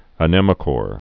(ə-nĕmə-kôrē)